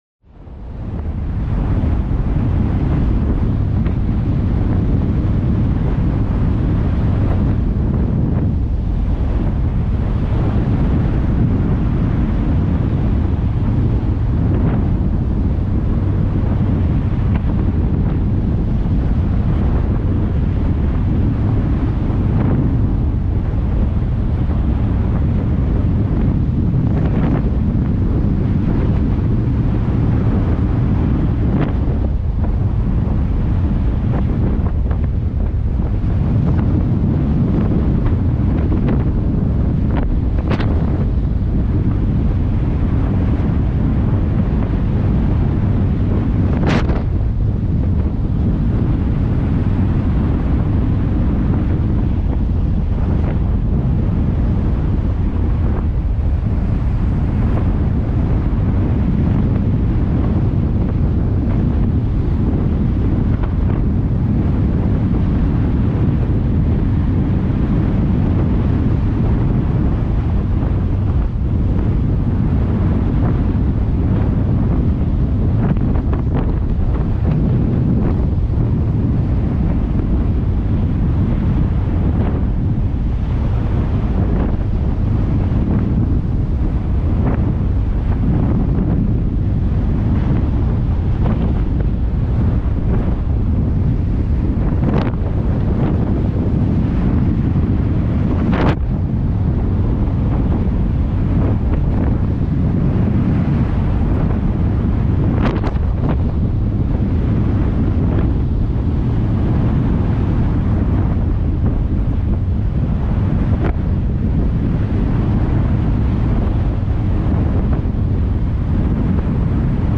cargoship-sound.mp3